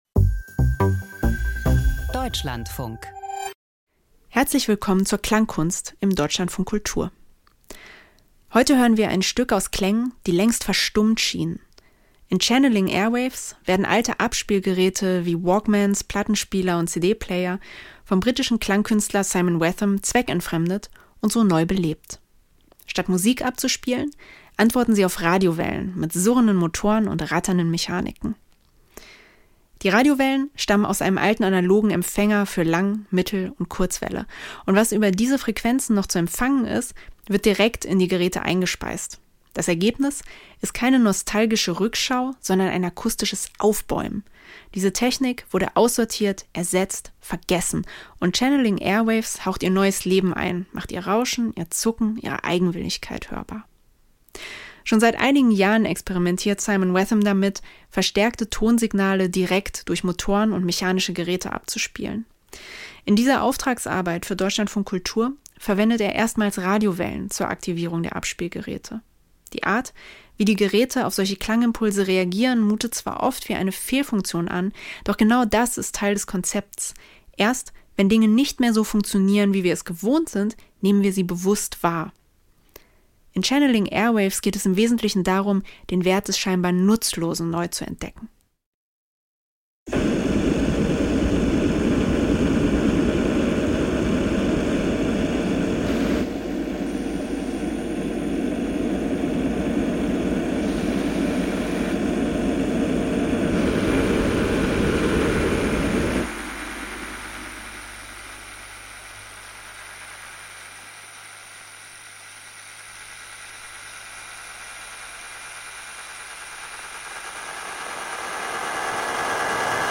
Krimi-Hörspiel: Mord in der Waschanlage - Wash and Kill